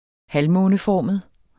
Udtale [ -ˌfɒˀməð ]